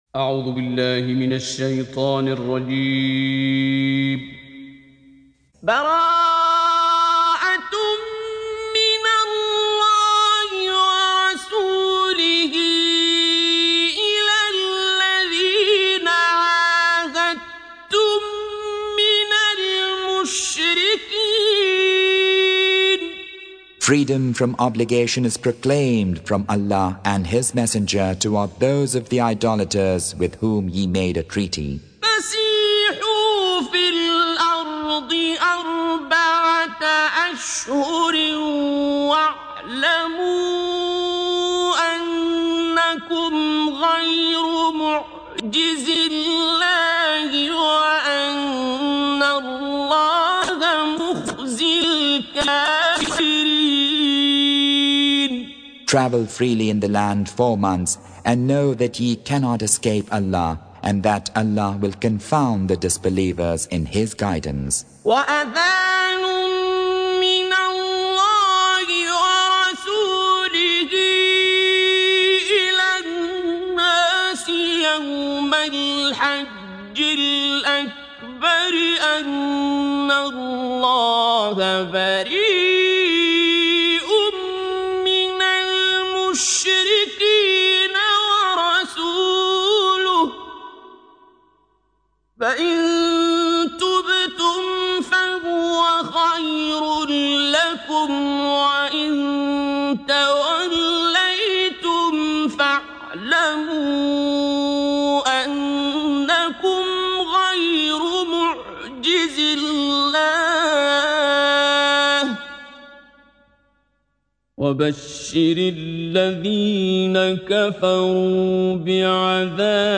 Surah Sequence تتابع السورة Download Surah حمّل السورة Reciting Mutarjamah Translation Audio for 9. Surah At-Taubah سورة التوبة N.B *Surah Excludes Al-Basmalah Reciters Sequents تتابع التلاوات Reciters Repeats تكرار التلاوات